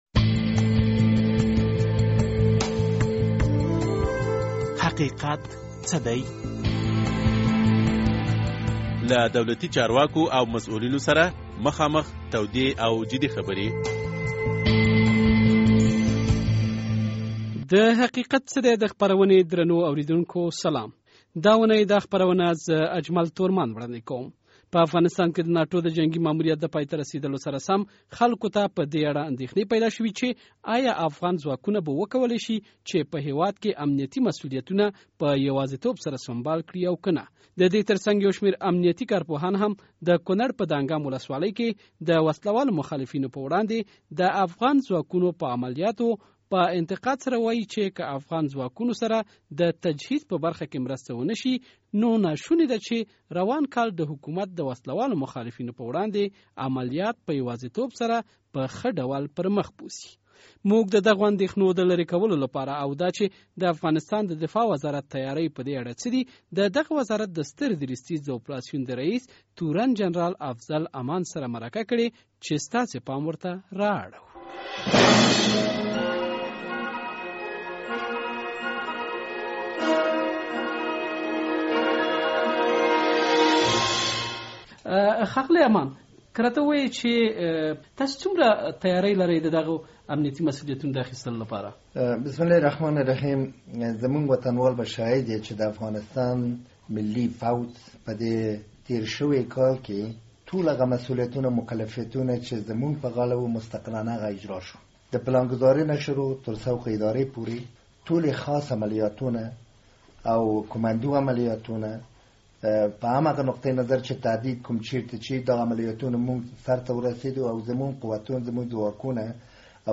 د حقیقت څه دی په دې خپرونه کې مو د افغان ځواکونو د وړتیا په اړه د افغانستان د ستر درستیز د اپراسیون د ریس تورن جنرال افضل امان سره مرکه کړې ده.